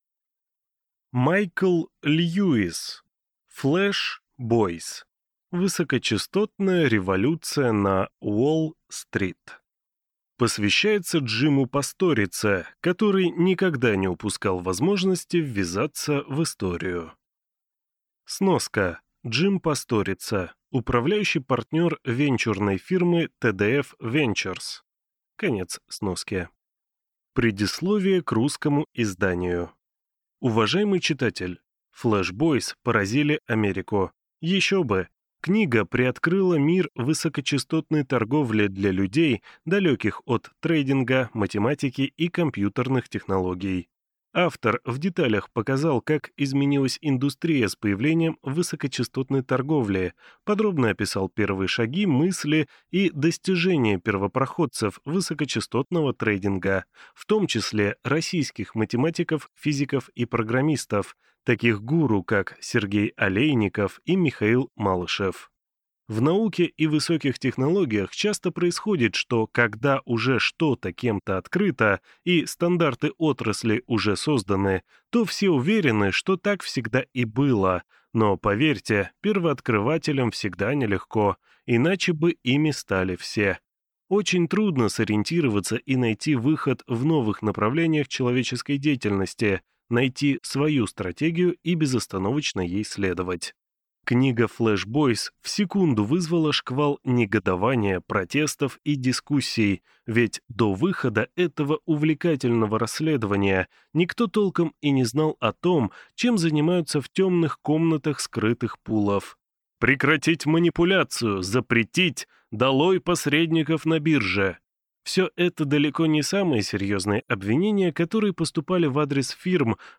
Аудиокнига Flash Boys. Высокочастотная революция на Уолл-стрит | Библиотека аудиокниг